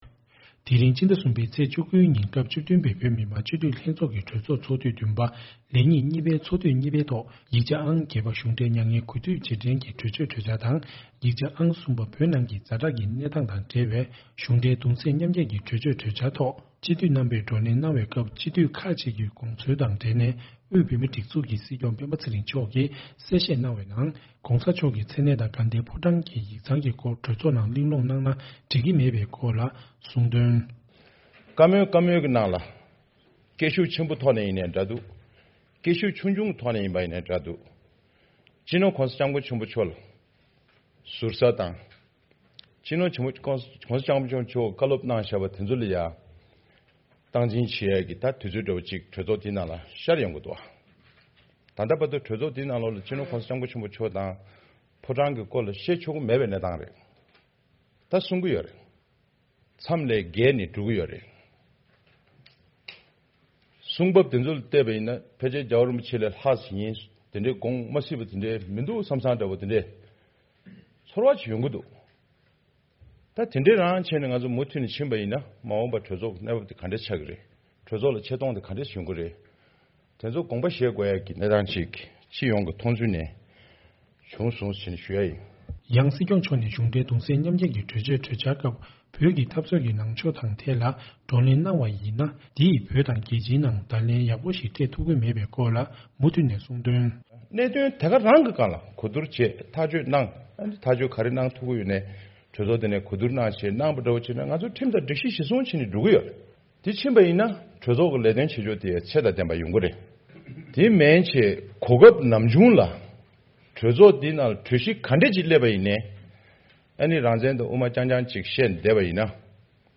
བོད་ནང་གི་ཛ་དྲག་གི་གནས་སྟངས་དང་འབྲེལ་བའི་གཞུང་འབྲེལ་གདུང་སེམས་མཉམ་བསྐྱེད་ཀྱི་གྲོས་ཆོད་གྲོས་འཆར་ཐོག་སྤྱི་འཐུས་རྣམ་པས་བགྲོ་གླེང་གནང་བའི་སྐབས་སྤྱི་འཐུས་ཁག་ཅིག་གི་དགོངས་ཚུལ་དང་འབྲེལ་ནས་བོད་མིའི་སྒྲིག་འཛུགས་ཀྱི་སྲིད་སྐྱོང་སྤེན་པ་ཚེ་རིང་མཆོག་གིས་གསལ་བཤད་གནང་བ།